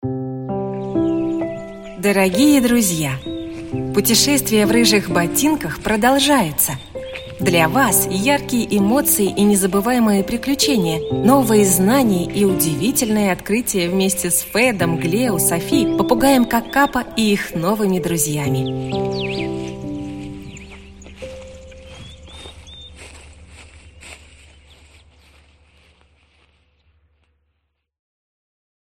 Aудиокнига Новые путешествия в рыжих ботинках Автор Оксана Анурова Читает аудиокнигу Центр звукового дизайна ЗВУКАРНЯ.